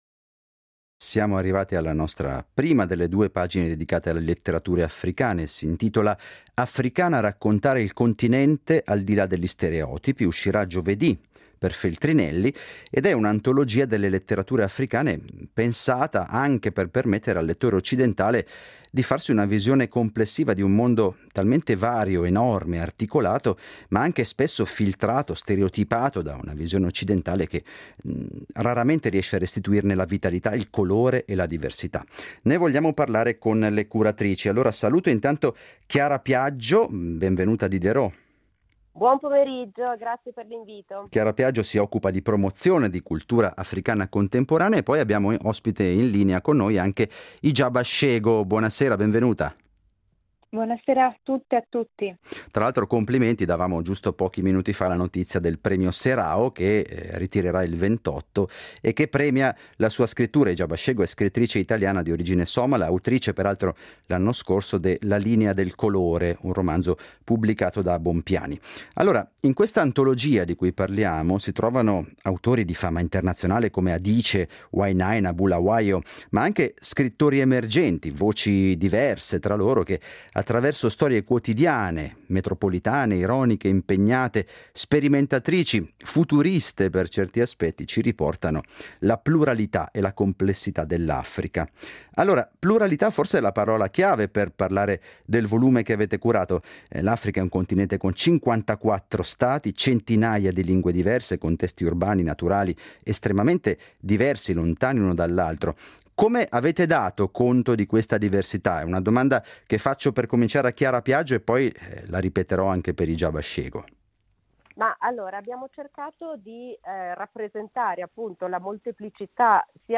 Si tratta di un’antologia delle letterature africane, pensata per permettere al lettore occidentale di avere una visione complessiva di un mondo enormemente vario e articolato, spesso filtrato e stereotipato da una visione occidentale che spesso non sa restituirne la vitalità e il colore. Ne parliamo con le curatrici.